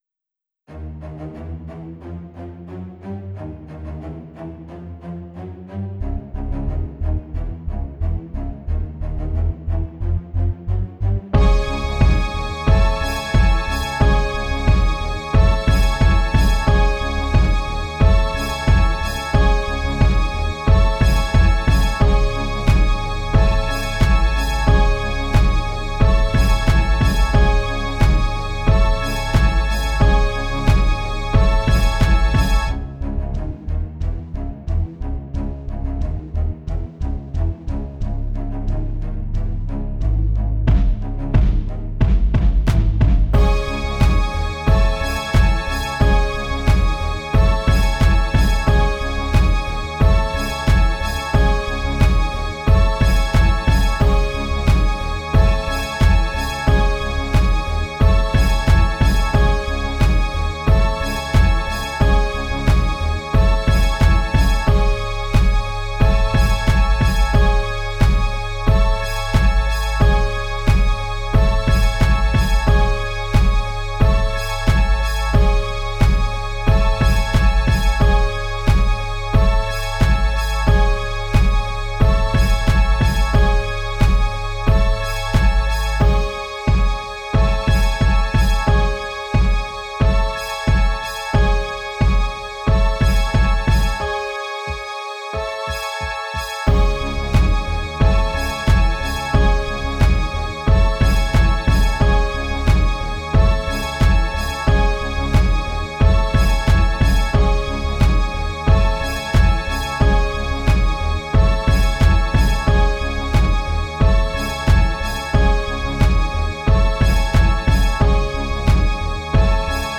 instrumental songs by design